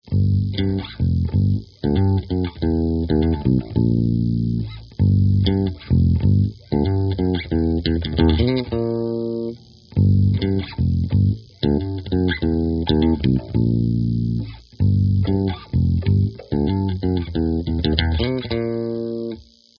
Der Song mischt Alternative Rock und Flamenco-Pop.